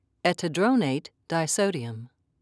(e-ti-droe'nate)